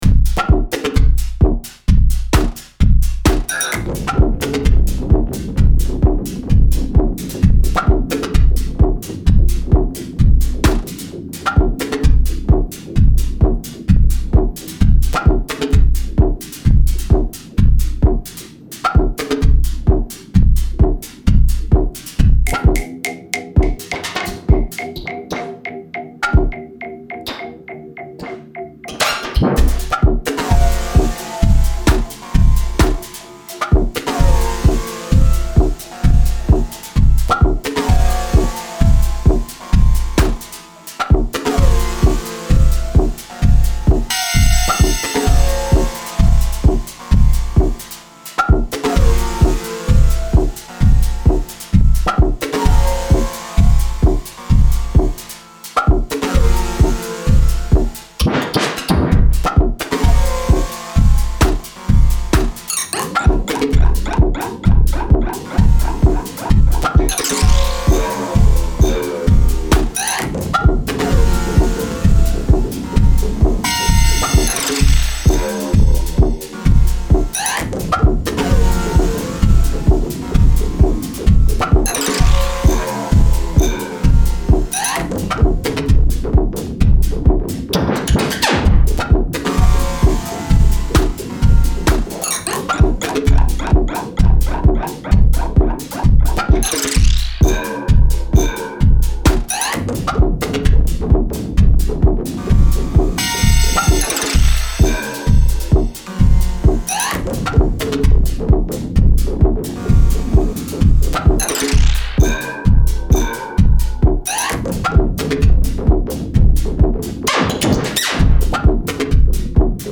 lyra-8 sampled into renoise, including vocals